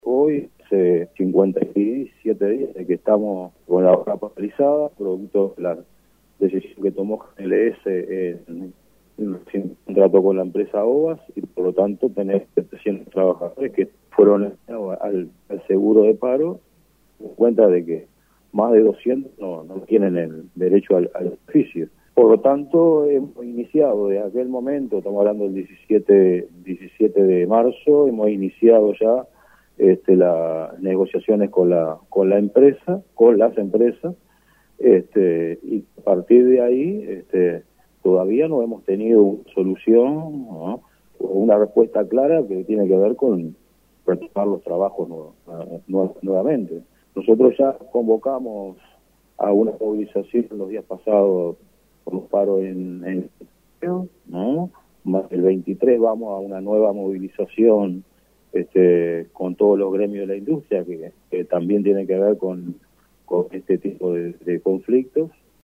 En diálogo con radio El Espectador